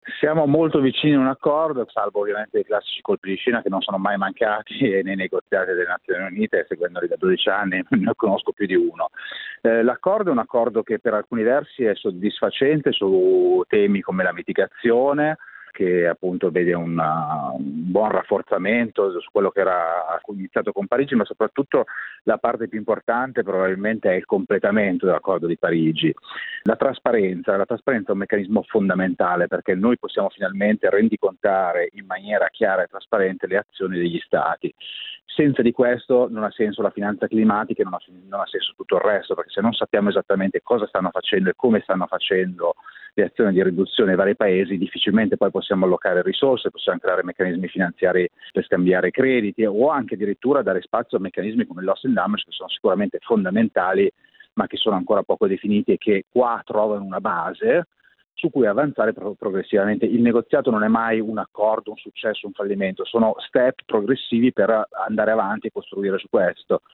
A Glasgow abbiamo sentito il giornalista ambientale